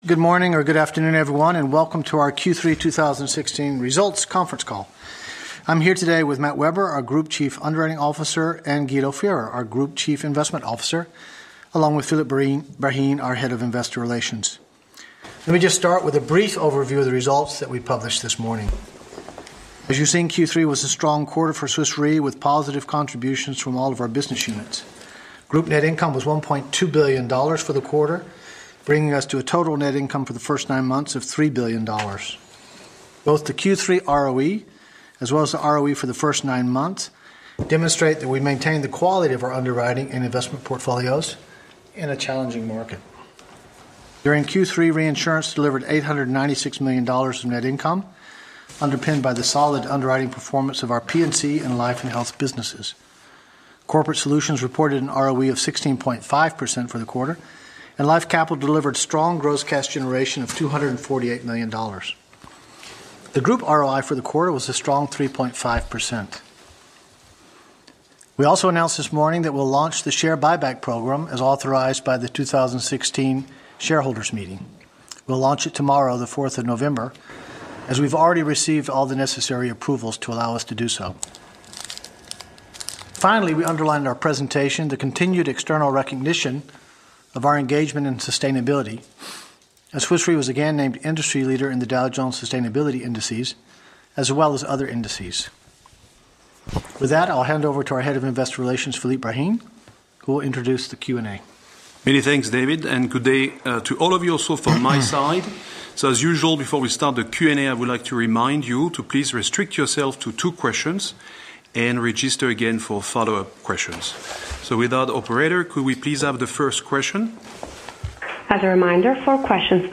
Analysts Conference call recording
2016_q3_qa_audio.mp3